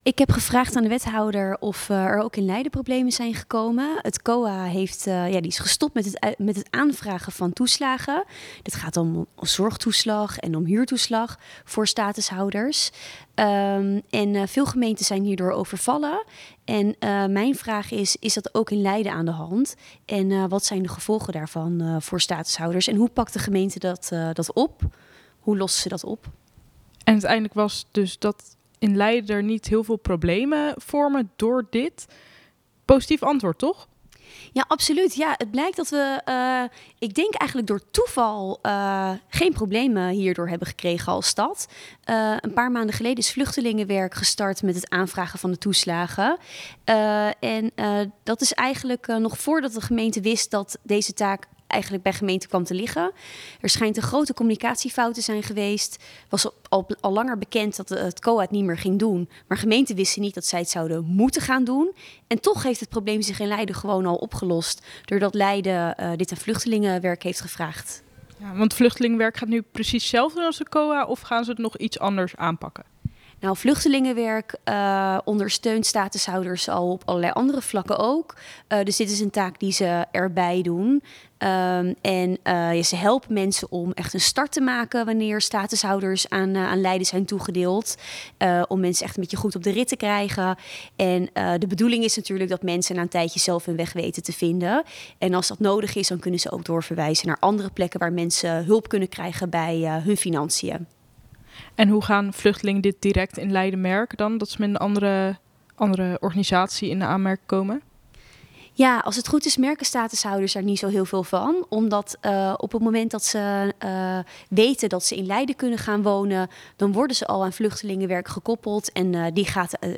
in gesprek met Van Noort over de gevolgen van dit besluit.